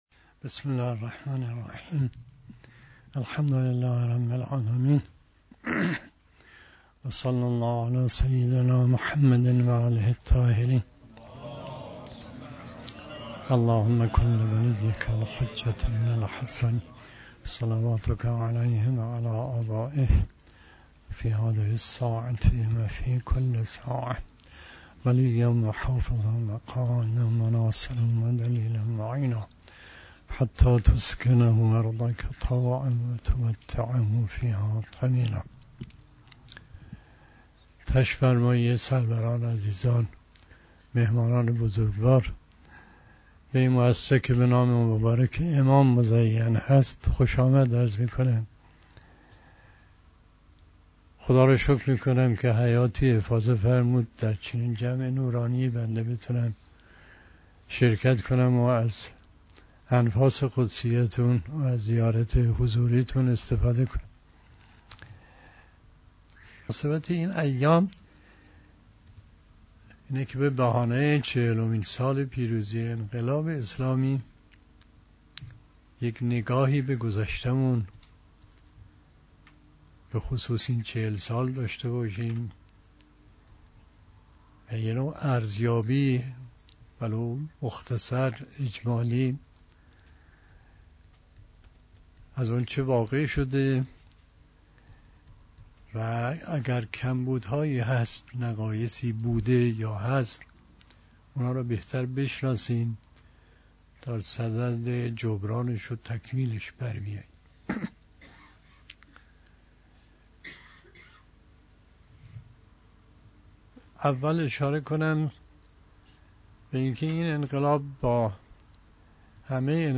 در این بخش از ضیاءالصالحین، صوت بیانات ارزشمند علامه محمـد تقـی مصباح یزدی (ره) را در مورد "غفلت از اهداف انقلاب" به مدت 45 دقیقه می شنوید.
⫸ حضرت آیت الله مصباح یزدی، رئیس مؤسسه آموزشی و پژوهشی امام خمینی (ره) در دیدار با جمعی از اساتید دانشگاه علوم پزشکی شهید صدوقی یزد و روحانیان سپاه قدس با اشاره به تفاوت انقلاب اسلامی و سایر انقلاب های دنیا اظهار داشت: اکثر انقلاب هایی که در تاریخ رخ داده است با انگیزه قومی و مذهبی یا اقتصادی، سیاسی و اجتماعی بوده است، اما هدف از انقلاب اسلامی ما فراتر از این انگیزه ها بود.